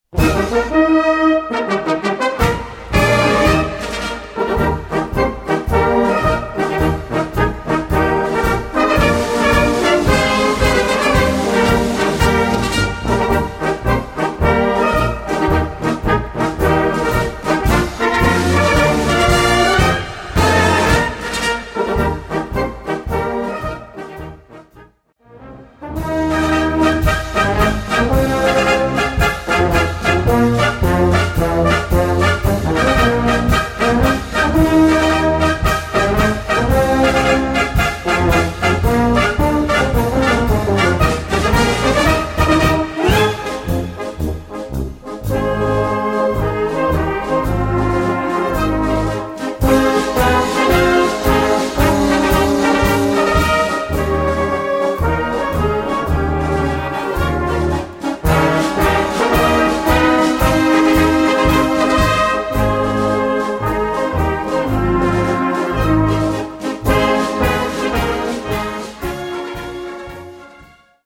Blasorchester
Schwierigkeitsgrad: 2 (mittel)